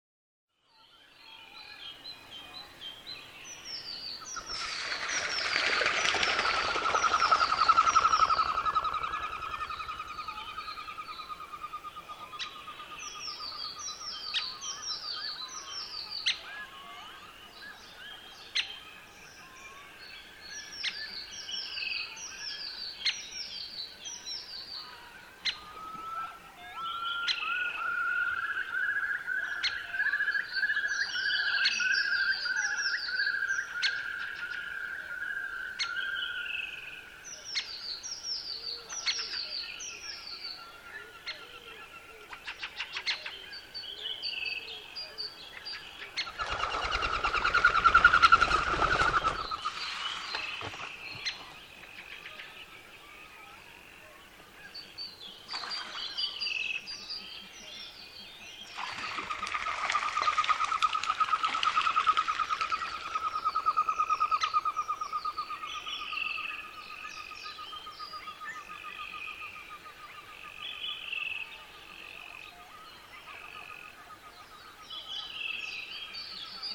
Suomen Luonto: Telk�n lento��ni
Telkk�parin soidinkisailuun kuuluu monia ��ni�. Yksi kuulu- vimmista on lent�v�n linnun siivist� l�htev� vihelt�v� ��ni.
telkka.mp3